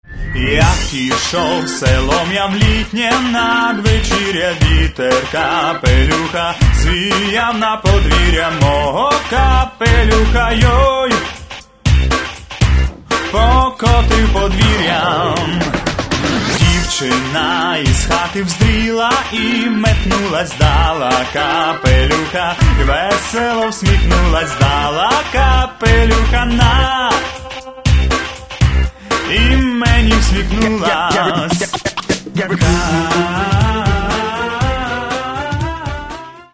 в исполнении современных поп-артистов.